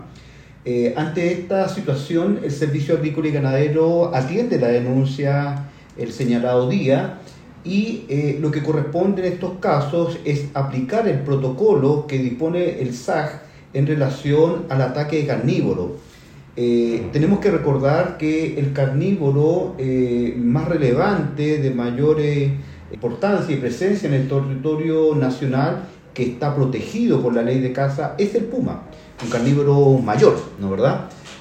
El director regional del SAG, Roberto Ferrada, junto con confirmar la presencia del animal carnívoro, dijo que se están adoptando las medidas que la ley indica para estos casos, ya que el puma es una especie protegida en la ley de caza.